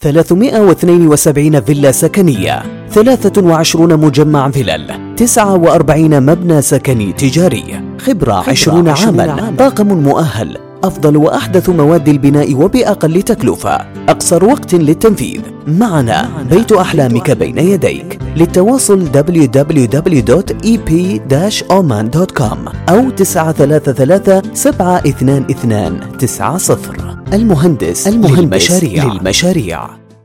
In Ramadan, we advertise on Oman Radio for reach our services to the largest segment is possible and also to participate in the advertising campaign in alroya newspaper , This is a vision of radio advertising